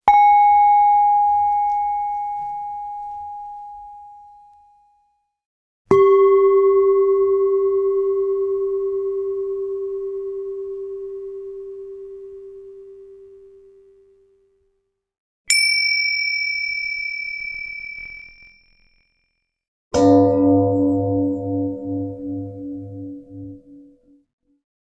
TibetanBowls_map.wav
tibetanbowls_map.wav